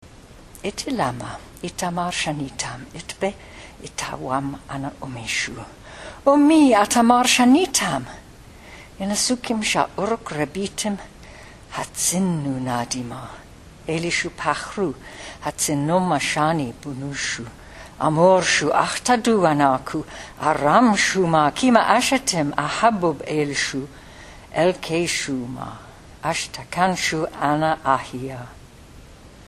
Evidently an ancient text read by a professional artist.
But it does strike me as a reading in some (to the speaker) foreign language by a person whose first language is almost certainly British English.